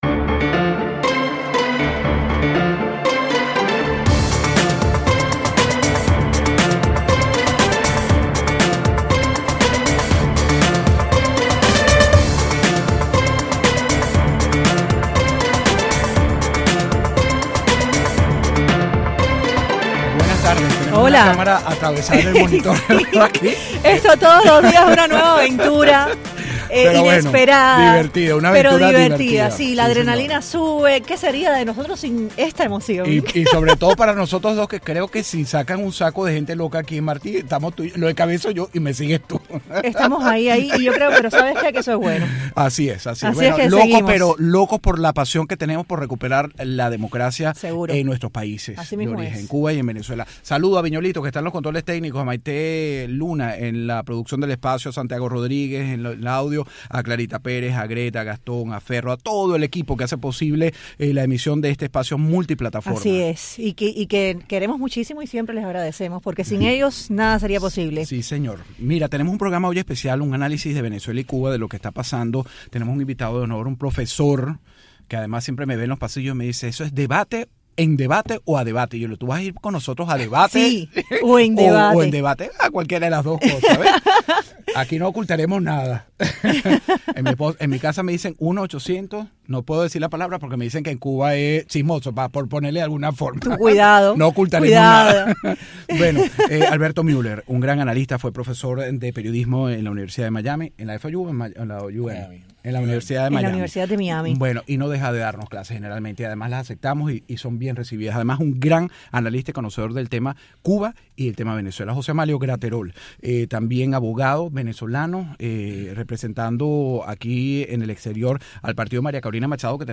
Hoy contamos en el debate